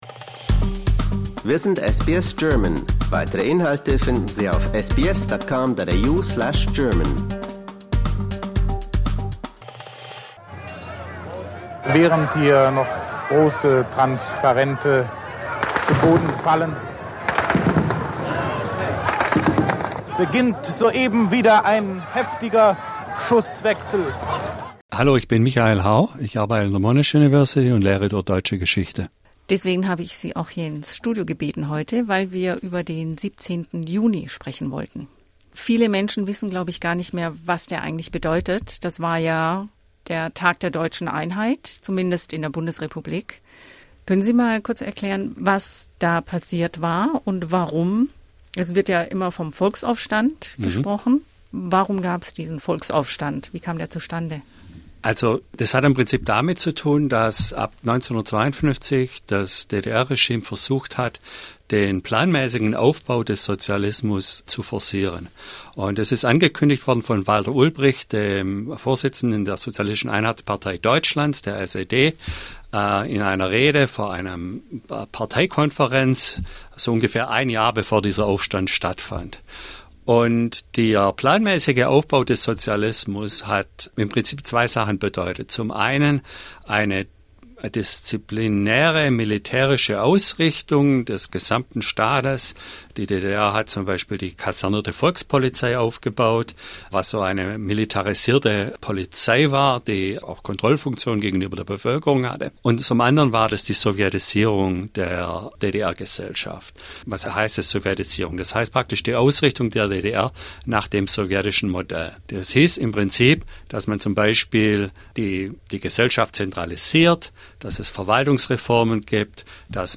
Doch worum ging es den geschätzt eine Million Bürgern und Bürgerinnen, als sie überall im Land auf die Straße gingen? Und warum gedachte Westdeutschland dem Tag 37 Jahre lang? Wir klären die Hintergründe im Gespräch